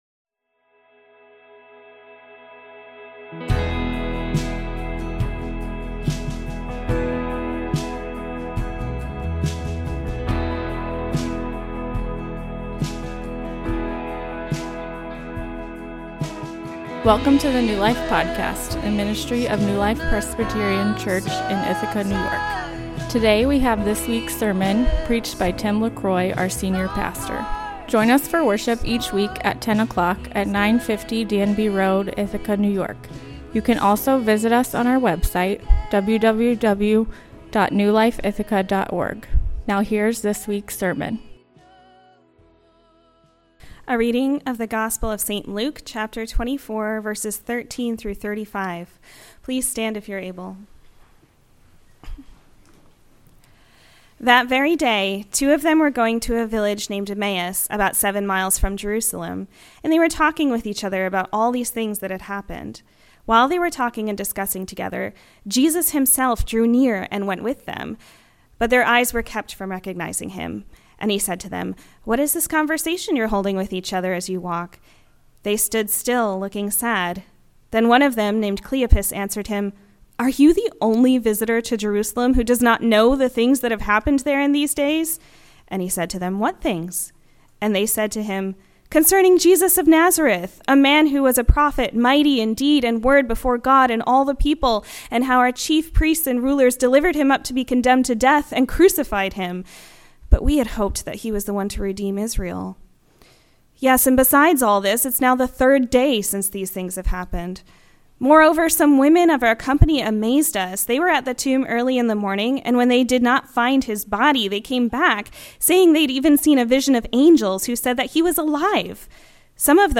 A sermon on the Road to Emmaus Sermon Outline: Problem: We seek the living among the dead I. We find Jesus in the Word preached II. We find Jesus in the Church